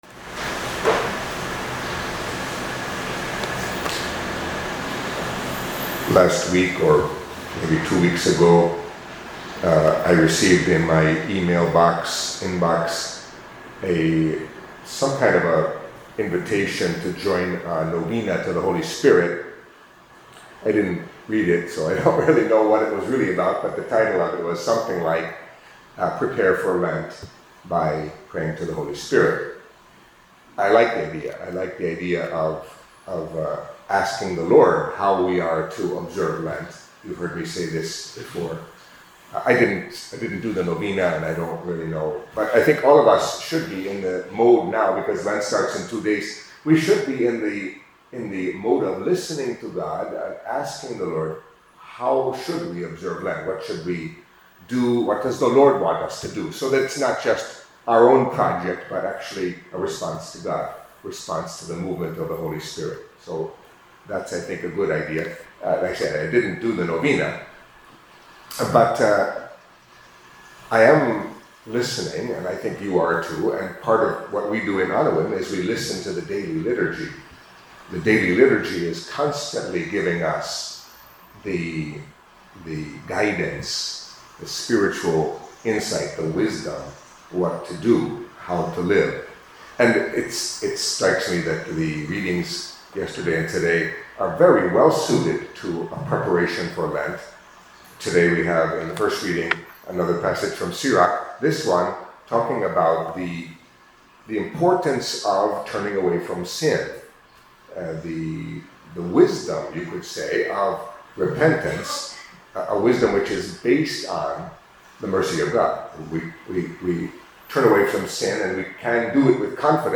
Catholic Mass homily for Monday of the Eighth Week in Ordinary Time